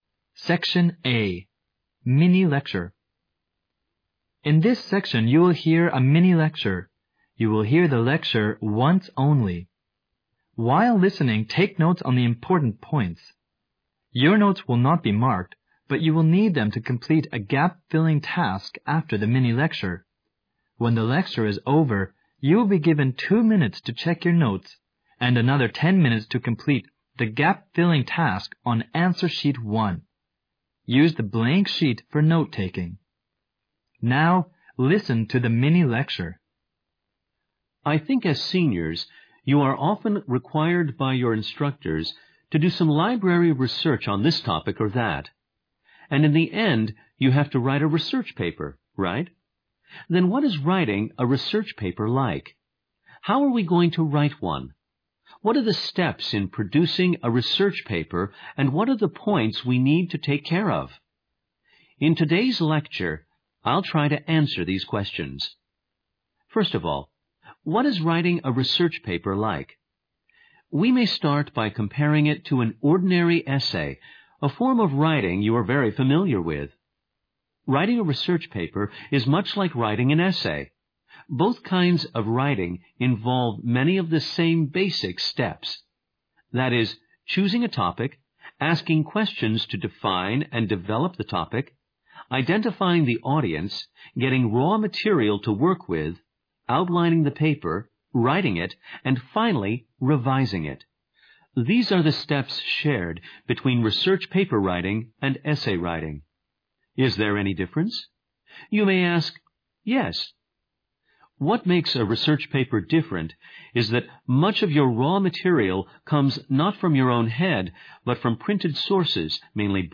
SECTION A MINI-LECTURE